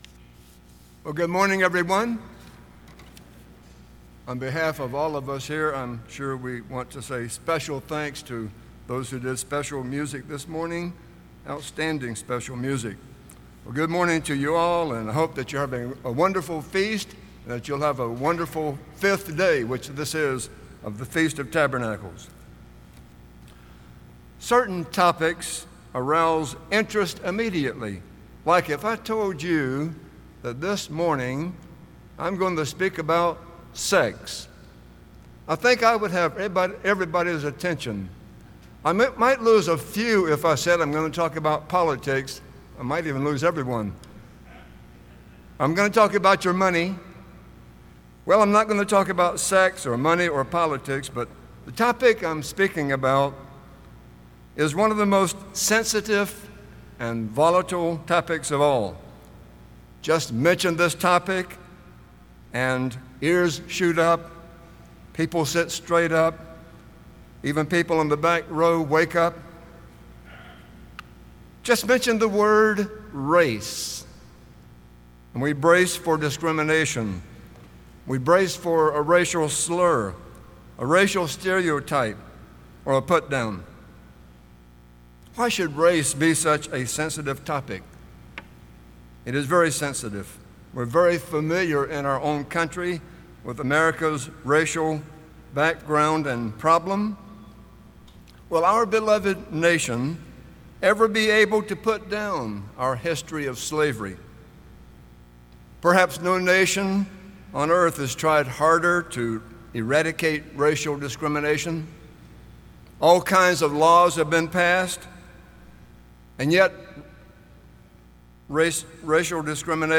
This sermon was given at the Gatlinburg, Tennessee 2020 Feast site.